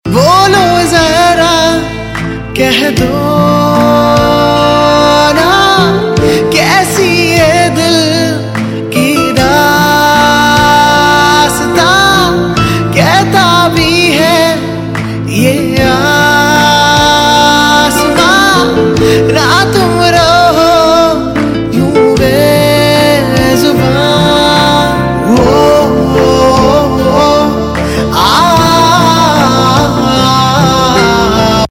Indian POP Ringtones